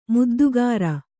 ♪ muddugāra